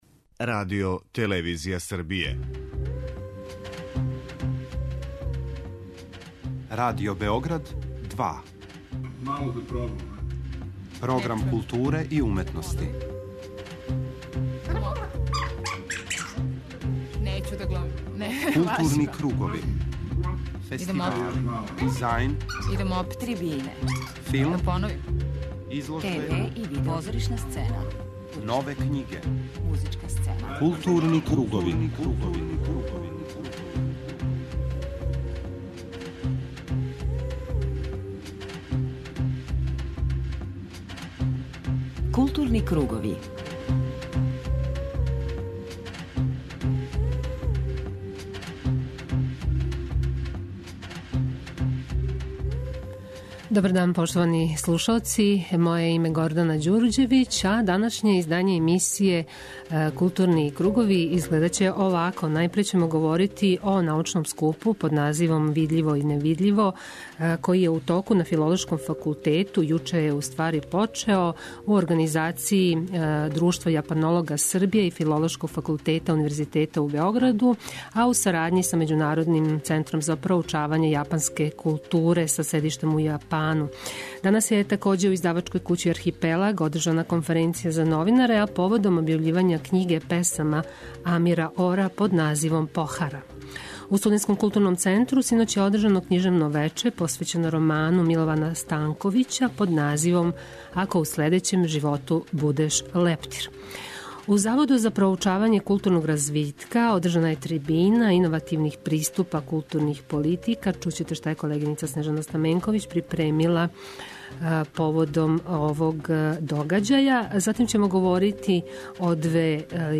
Гост Маски биће глумац Бранислав Лечић, који ће говорити о представи "Уносно место", Александра Николајевича Островског, у режији Егона Савина, чија је премијера била овог викенда у Југословенском драмском позоришту.